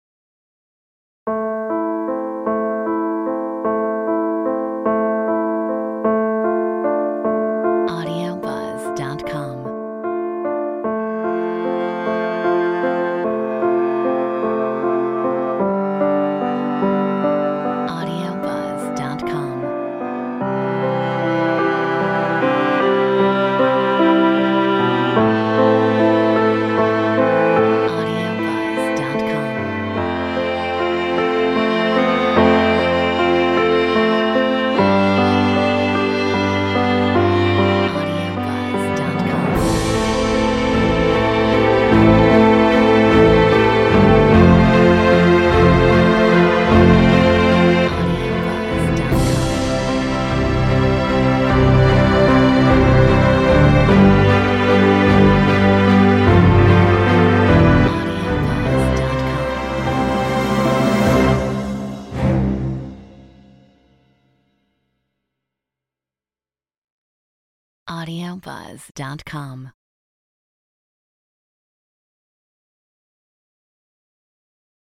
Metronome 77